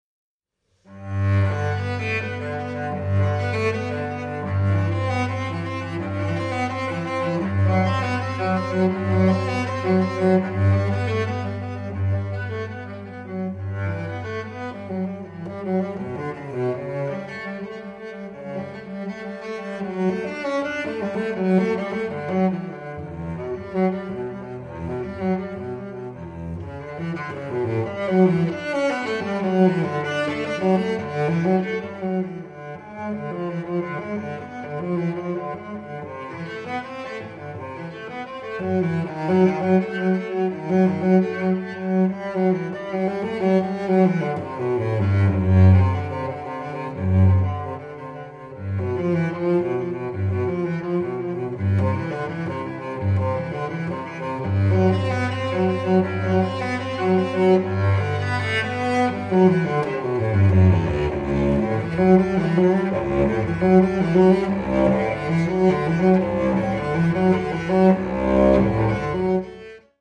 ★「音樂貴婦」大提琴美妙音色的最高境界，從豐厚飽滿到開朗明亮，充滿強烈旋律表現性！